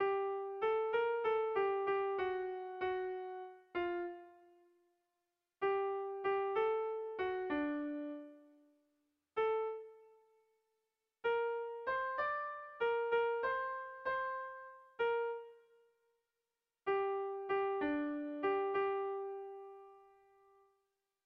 Erlijiozkoa
AB